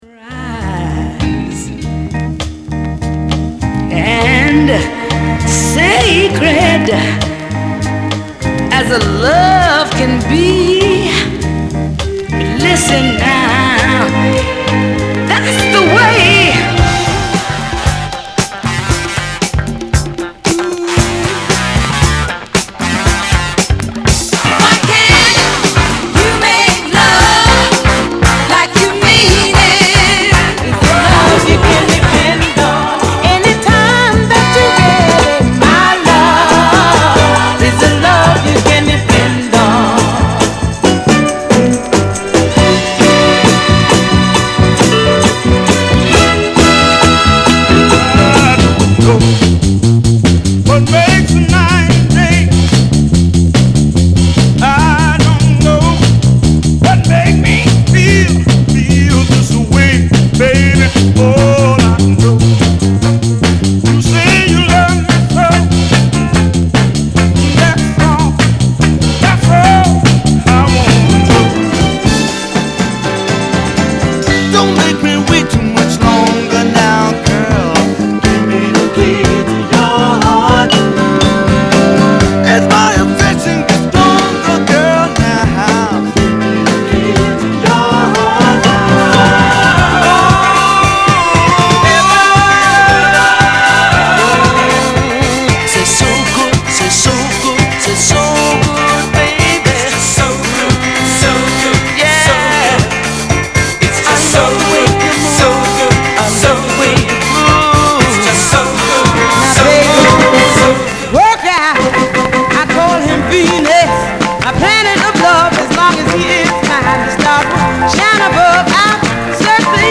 category R&B & Soul
is a great fast paced NS dancer